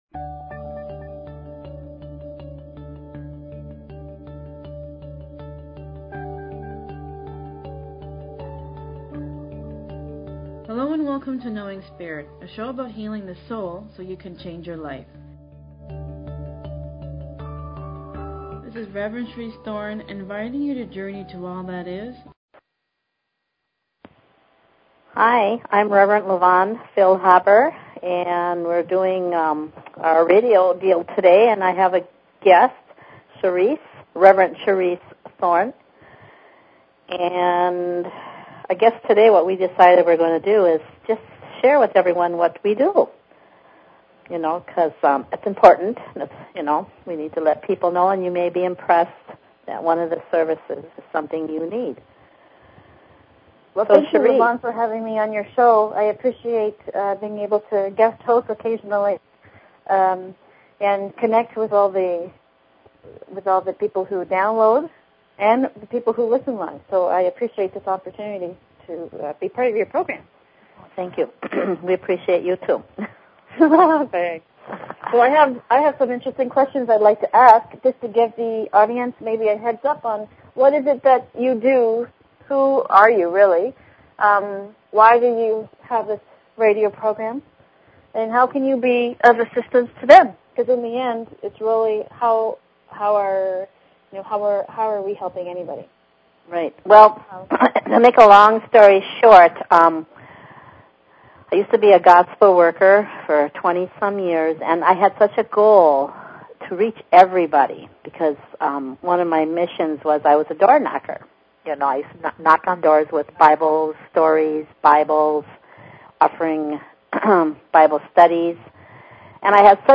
Talk Show Episode, Audio Podcast, Knowing_Spirit and Courtesy of BBS Radio on , show guests , about , categorized as